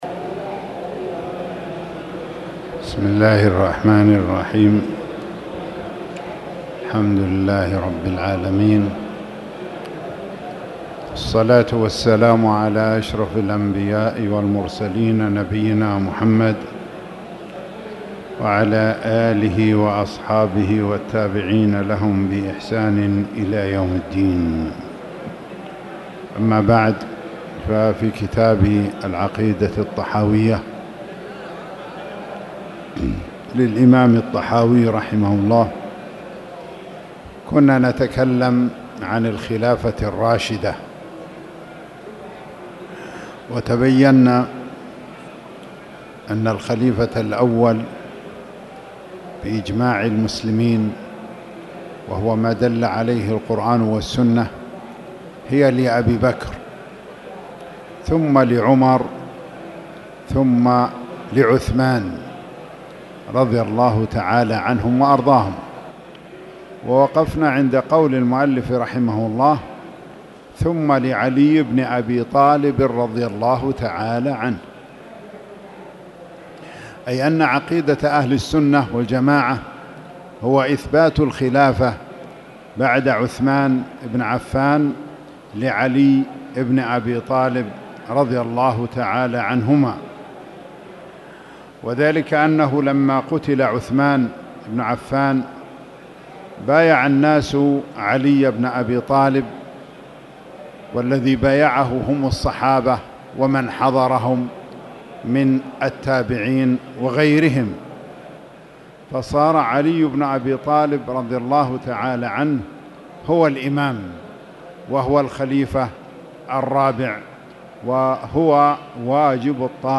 تاريخ النشر ٦ ربيع الأول ١٤٣٨ هـ المكان: المسجد الحرام الشيخ